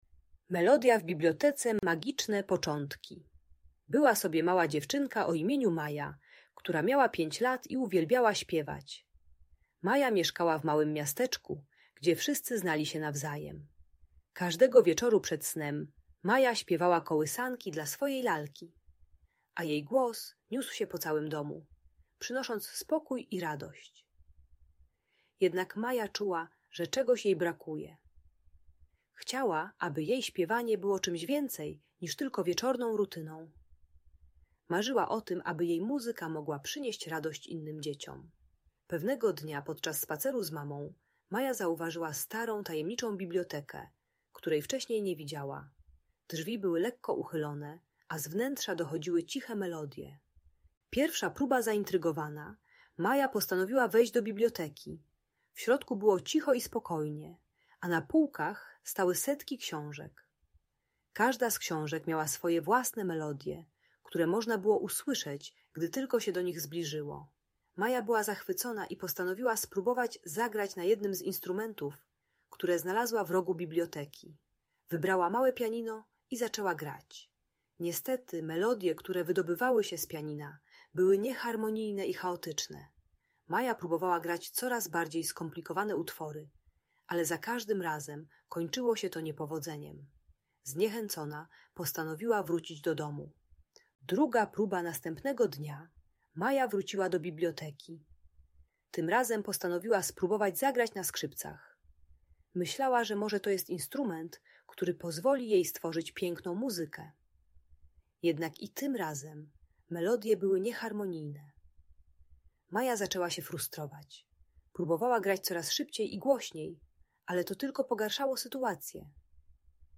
Przeznaczona dla dzieci 4-6 lat. Historia Mai uczy, że gdy czujemy frustrację, zamiast działać szybciej i głośniej, warto się zatrzymać, poprosić o pomoc i współpracować z innymi. Audiobajka o radzeniu sobie z frustracją i złością.